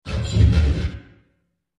These were made using the vocoder in FL studio, and edited again in Audacity.
A bit fuzzy, but they can be very useful for campaign-mods.